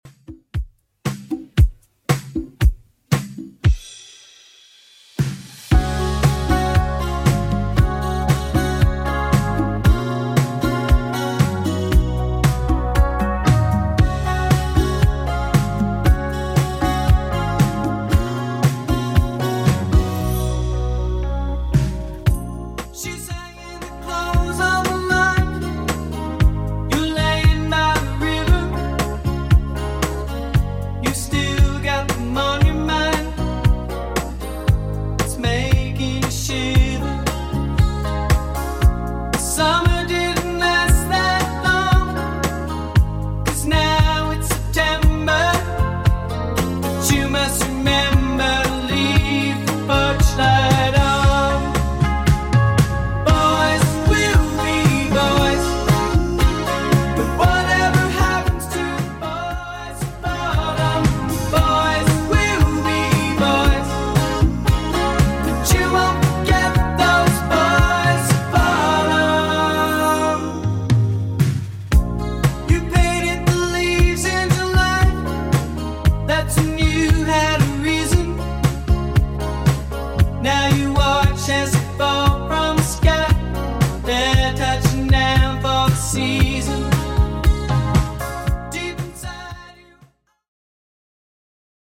Genre: 70's Version: Clean BPM: 121